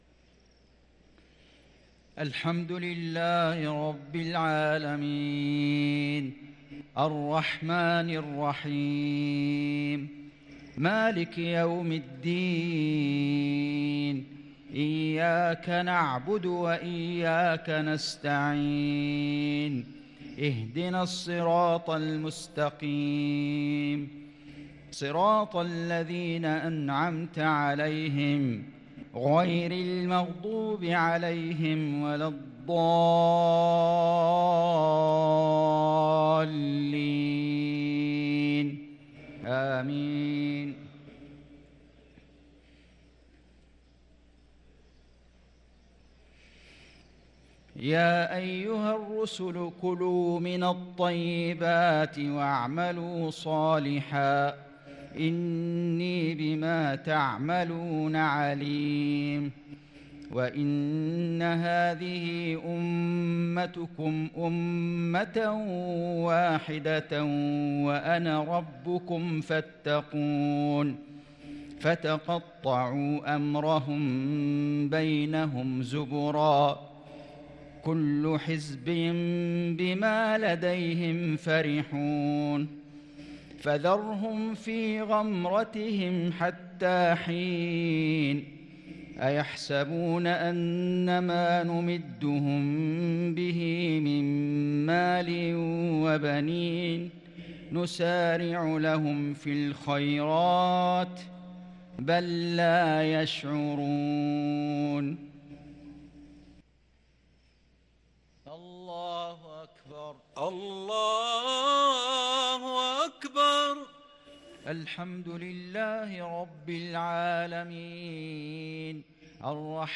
صلاة المغرب للقارئ فيصل غزاوي 4 رمضان 1443 هـ
تِلَاوَات الْحَرَمَيْن .